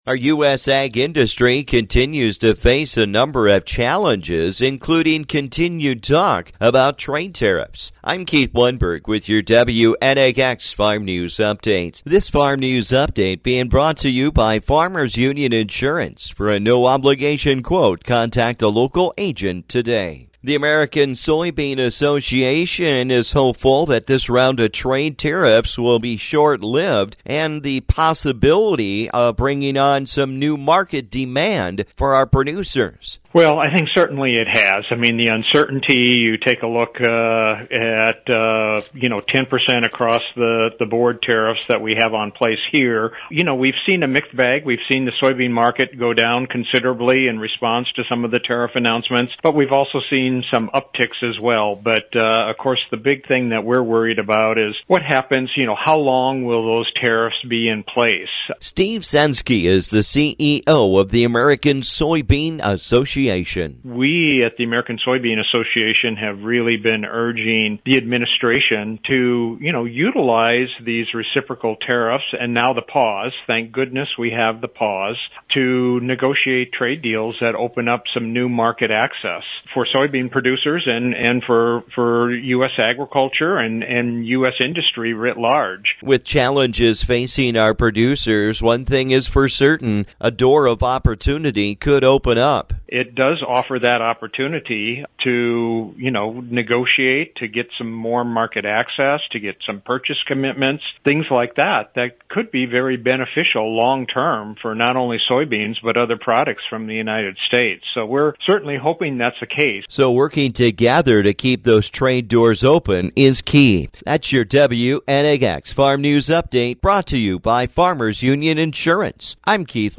With all the changes going on with our Trade Tariff talks, I talked with the American Soybean Association about some of the latest news.